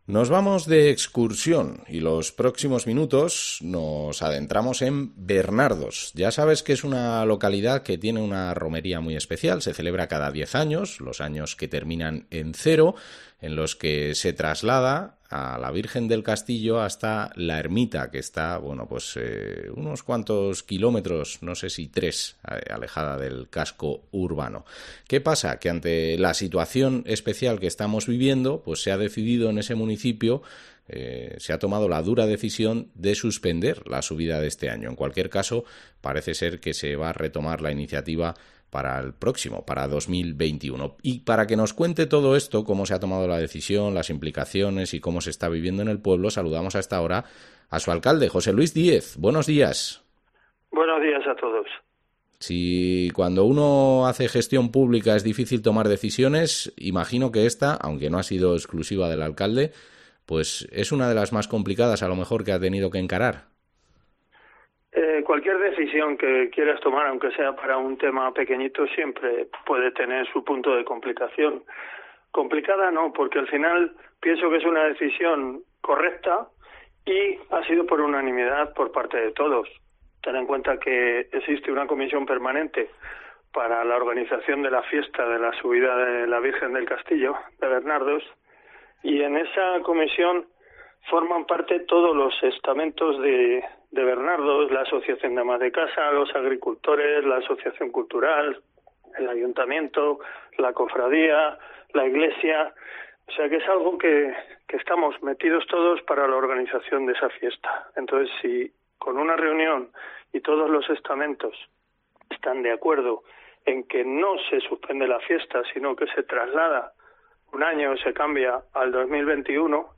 Entrevista a José Luis Díez, alcalde de Bernardos